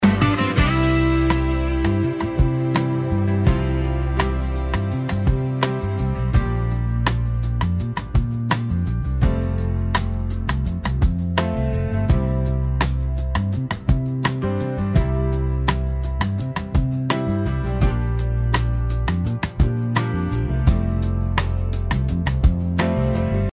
This track represents the C Mixolydian Mode.